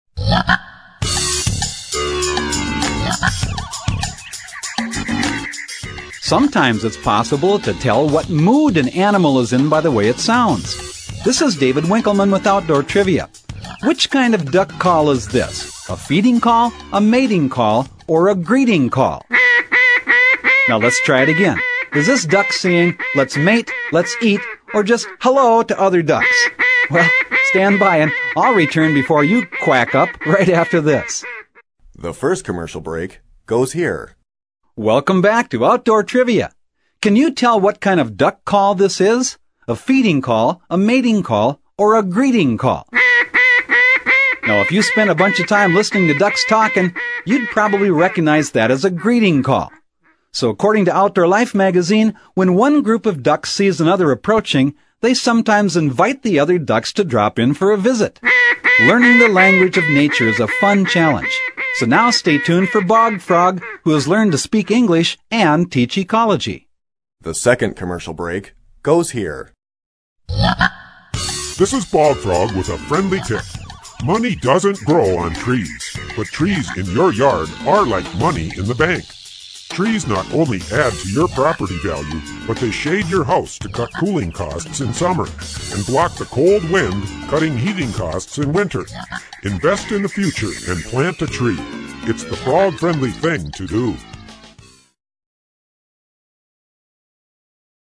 Tune in and play “Name that bird call!” Unlock the enigmas behind the sounds produced by gamebirds.
In fact, the question and answer trivia format of this program remains for